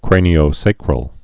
(krānē-ō-sākrəl, -săkrəl)